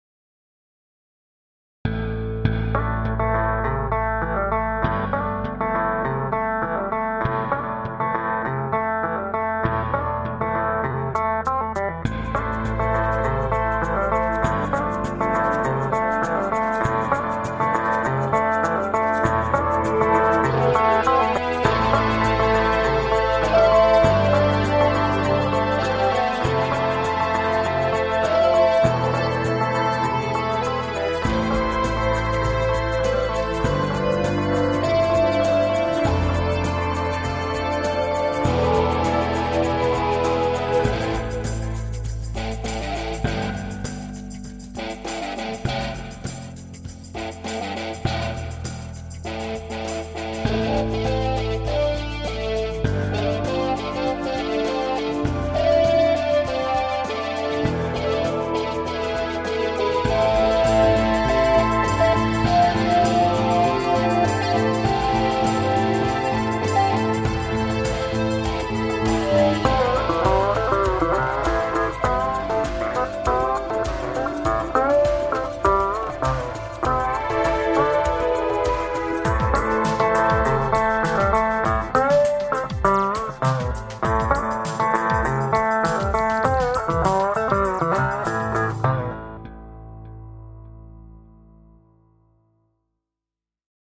The mood of the music is either action or emotion.
Southern-style, easygoing piece for the highway.